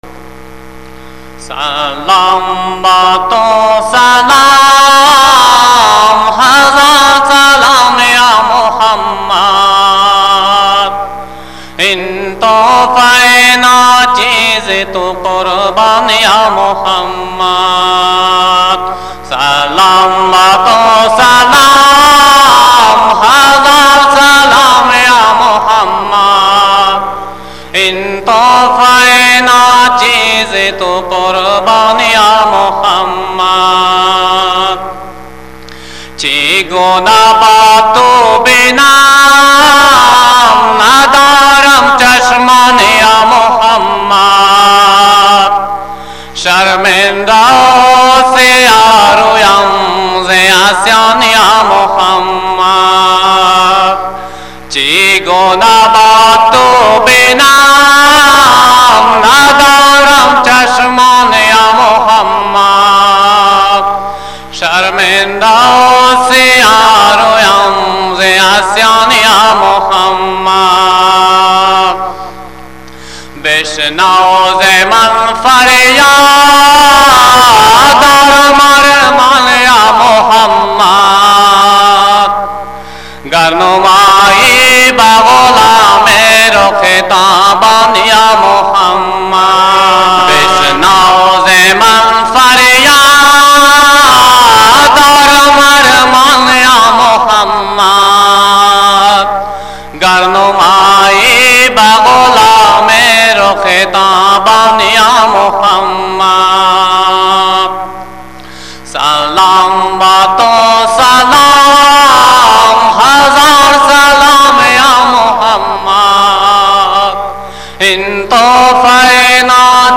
آڈیو نعتیں
Audio Naatain